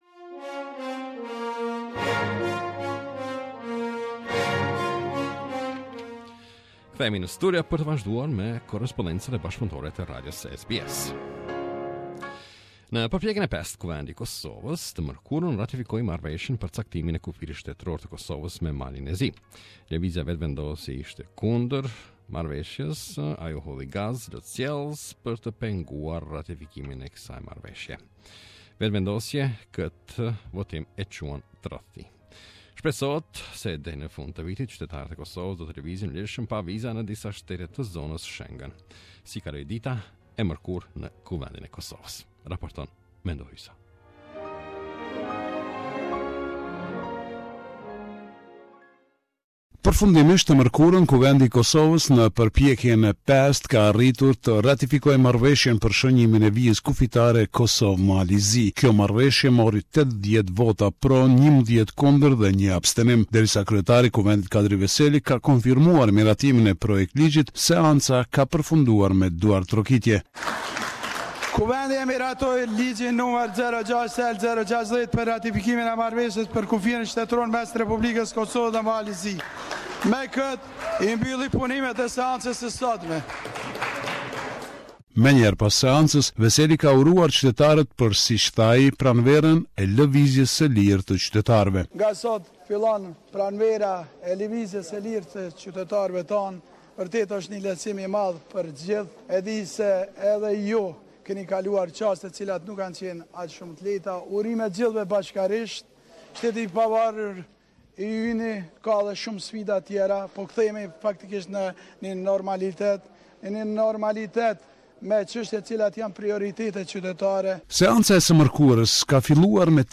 This is a report summarising the latest developments in news and current affairs in Kosovo